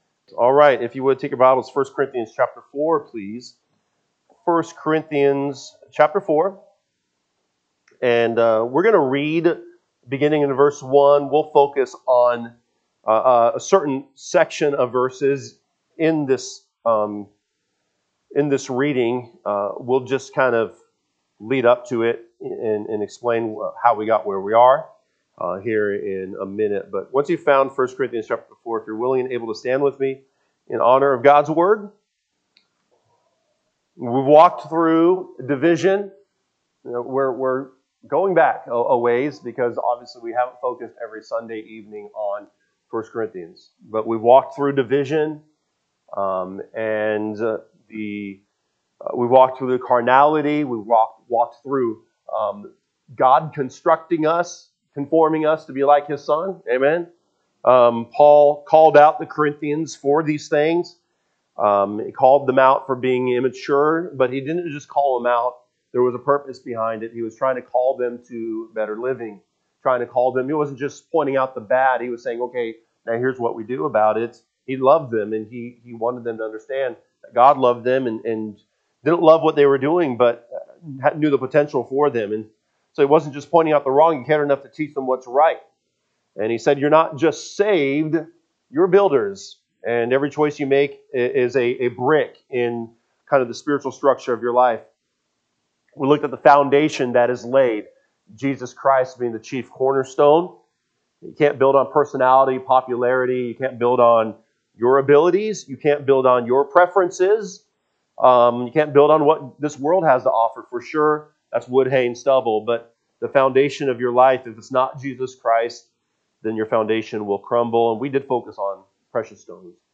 October 26, 2025 pm Service 1 Corinthians 4:1-13 (KJB) 4 Let a man so account of us, as of the ministers of Christ, and stewards of the mysteries of God. 2 Moreover it is required in stew…
Sunday PM Message